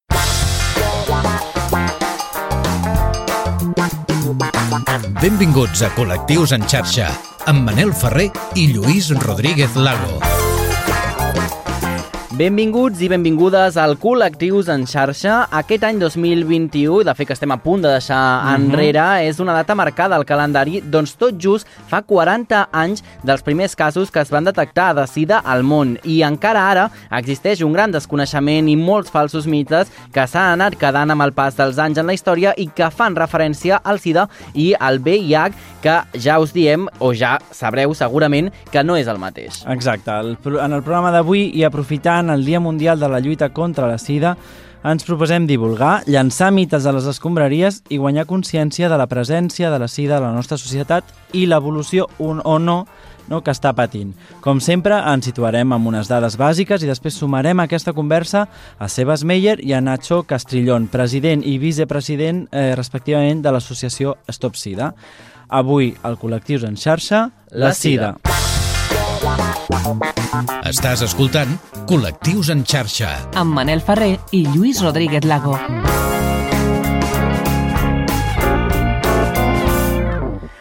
Indicatiu del programa, presentació de l'edició dedicada al Dia Mundial de la SIDA, quan feia 40 anys dels primers casos de SIDA al món, indicatiu del programa
Divulgació